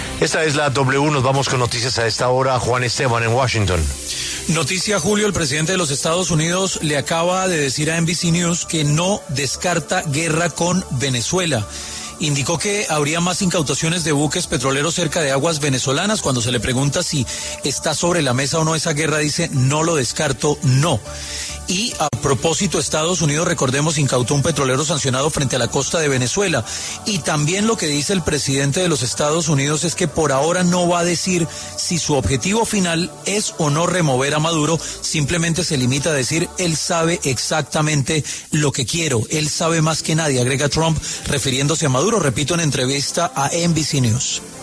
En una entrevista telefónica con NBC News, Trump declaró: “No lo descarto, no”, al ser consultado sobre la posibilidad de una guerra, y confirmó que continuarán las incautaciones de petroleros vinculados al país sudamericano.